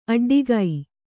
♪ aḍḍigai